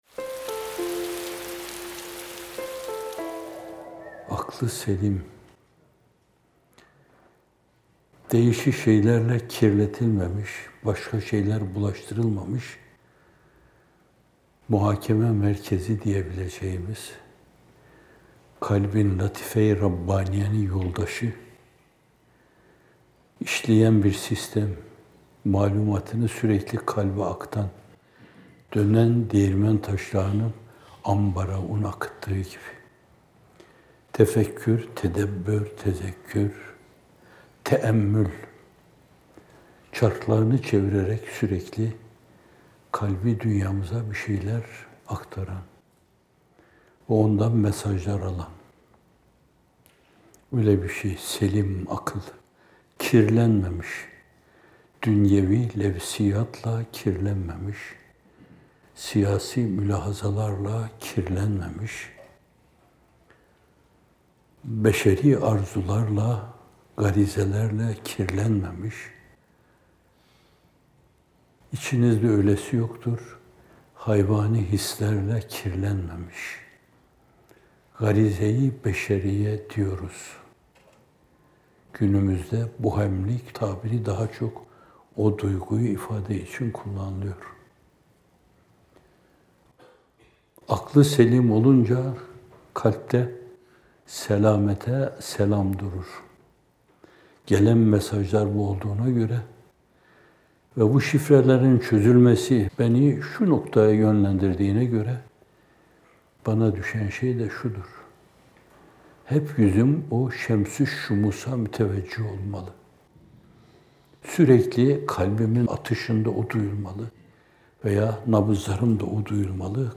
Not: Bu video, 1 Nisan 2019 tarihinde yayımlanan “Huzur Vesileleri ve Sohbet-i Cânân” isimli Bamteli sohbetinden hazırlanmıştır.